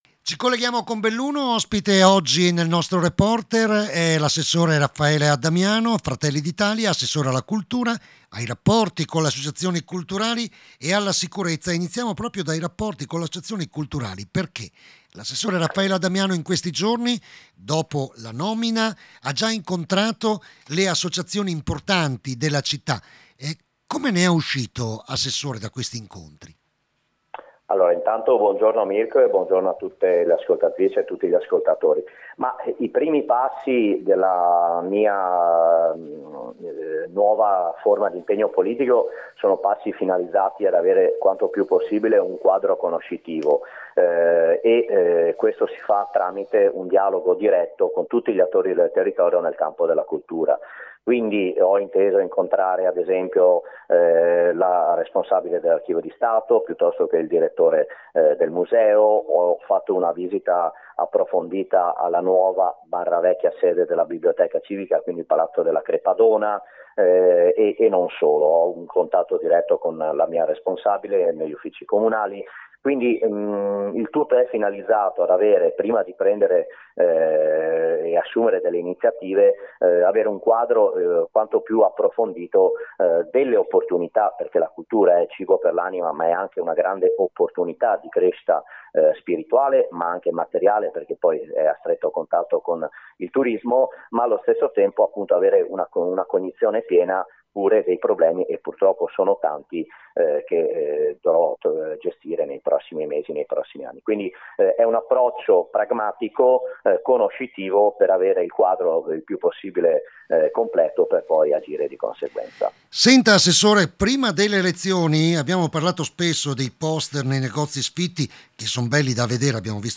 Domani una pattuglia dei vigili dei vigili della Polizia locale sarà nelle frazioni per distribuire materiale informativo per la prevenzione dei reati più diffusi. Giovedi torna il gazebo in via Sottocastello sempre con la collaborazione della Polizia locale. Ne abbiamo parlato con l’assessore alla cultura ed alla sicurezza Raffaele Addamiano.